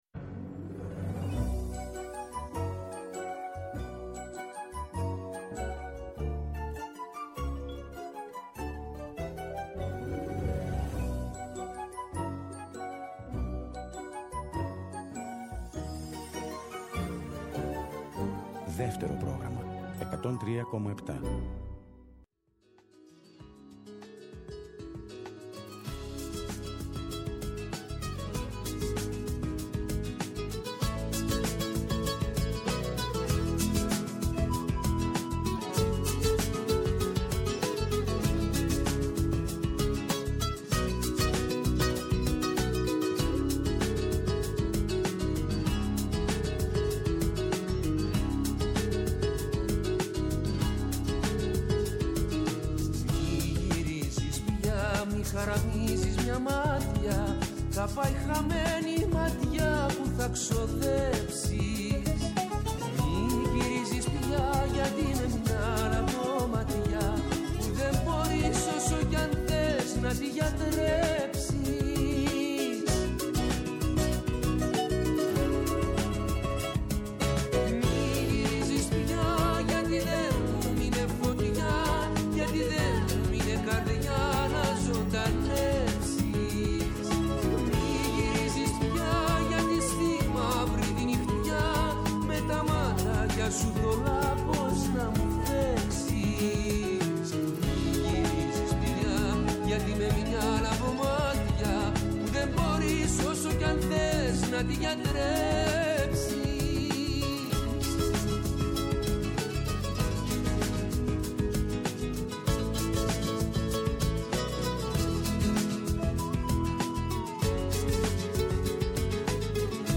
συνομιλεί τηλεφωνικά
Συνεντεύξεις